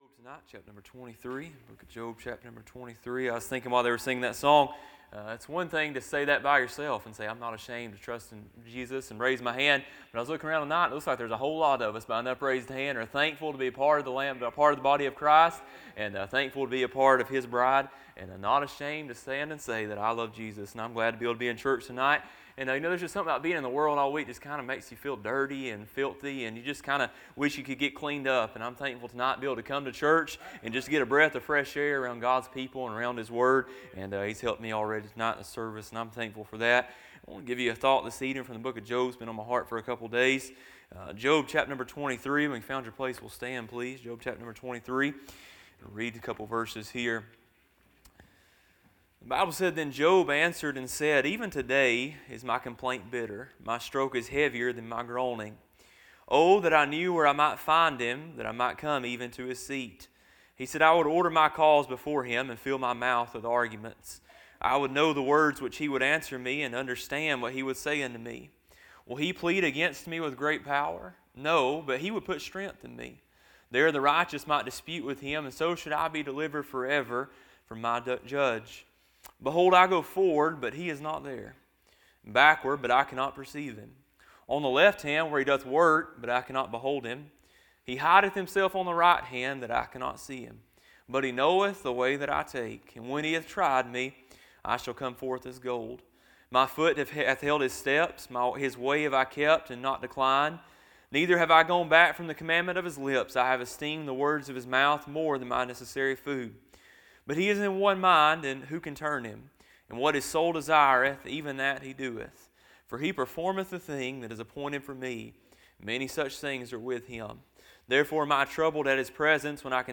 A sermon preached Wednesday Evening, on November 12, 2025.